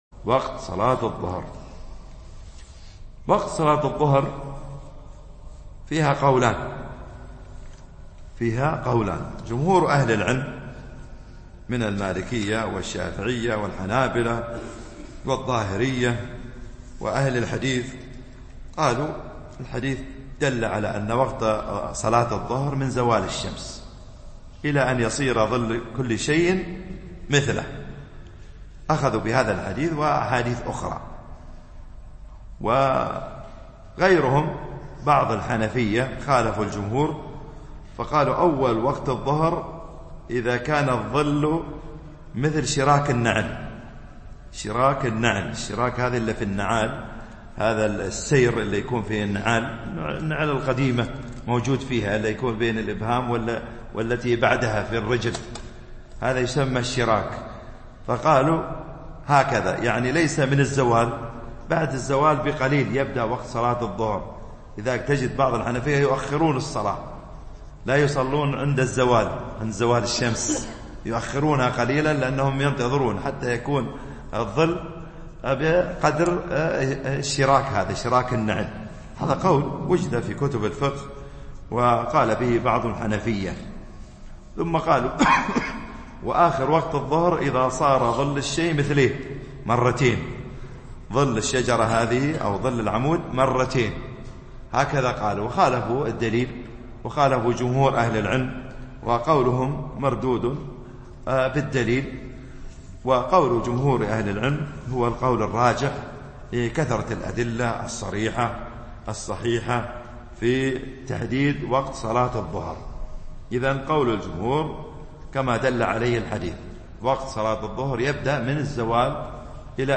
التنسيق: MP3 Mono 22kHz 64Kbps (CBR)